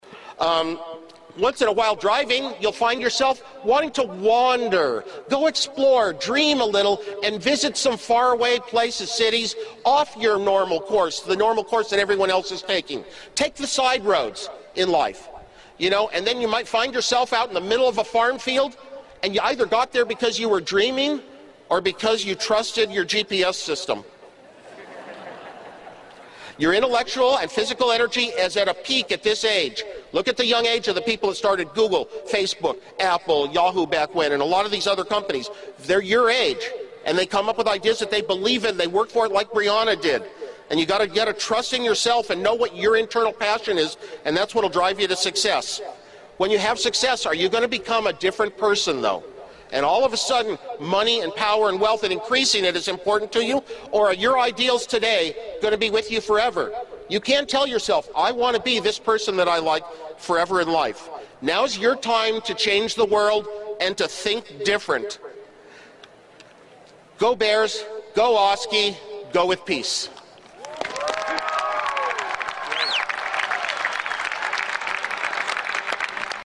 公众人物毕业演讲 第153期:史蒂夫·沃兹尼亚克于加州大学伯克利分校(10) 听力文件下载—在线英语听力室